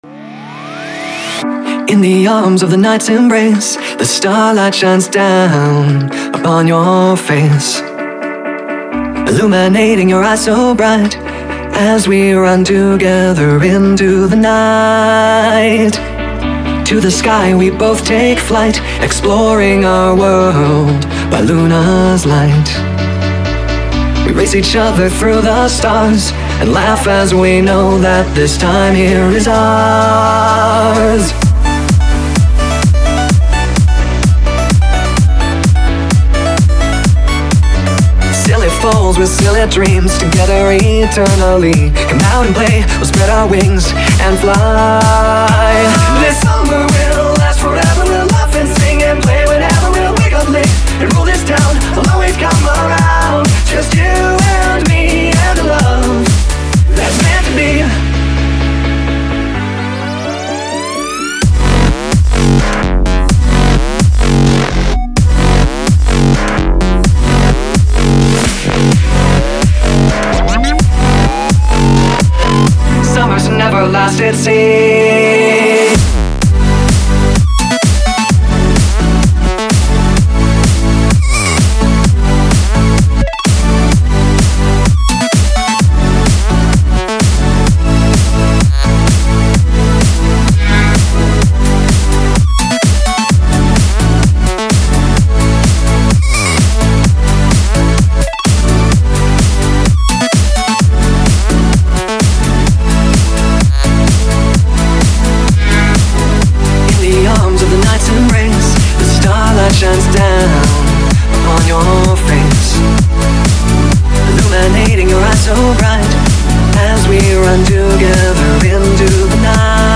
Bpm: 128 Genre: House